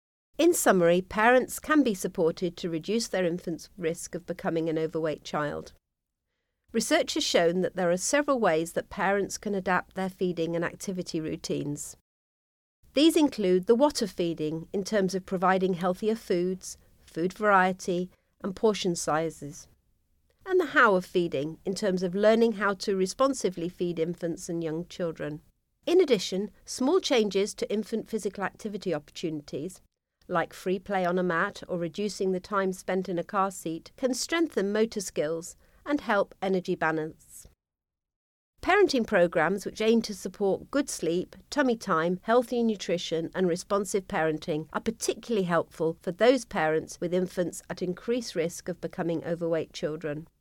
Previous Next Narration audio (MP3) Narration audio (OGG)